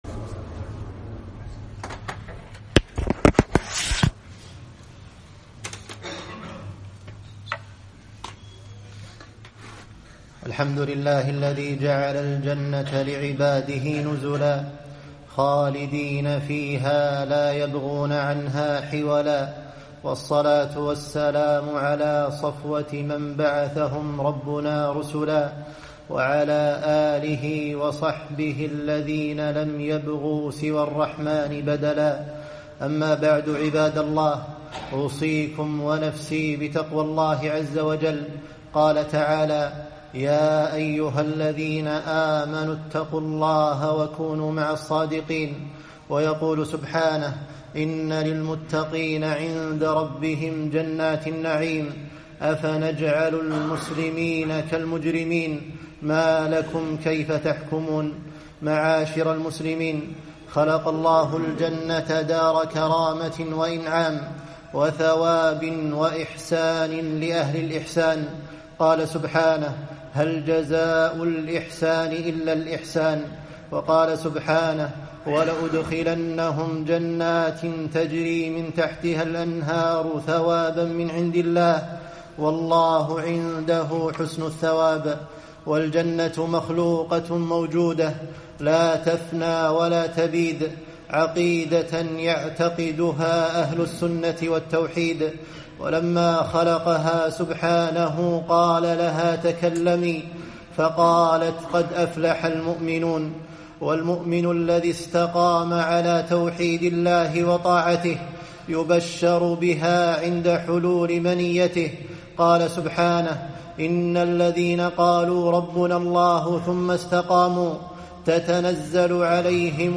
خطبة - نعيم الجنة